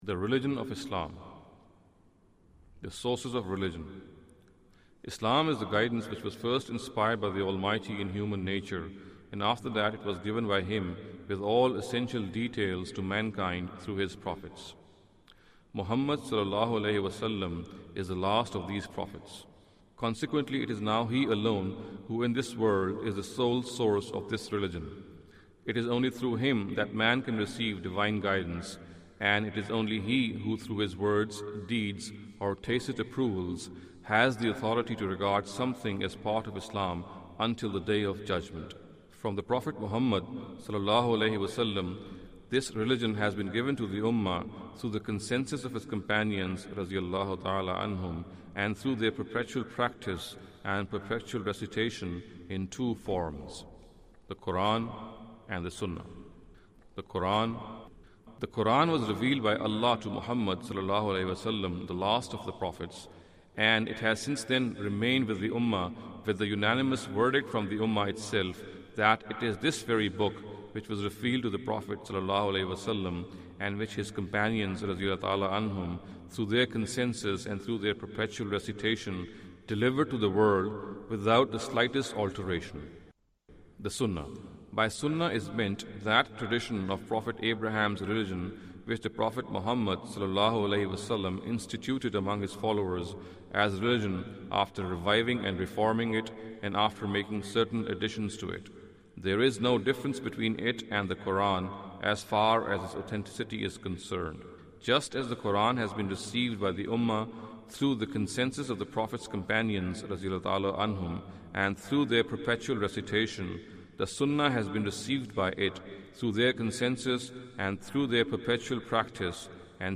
Audio book of English translation of Javed Ahmad Ghamidi's book "Islam a Concise Intro".